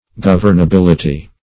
Governability \Gov"ern*a*bil"i*ty\, n.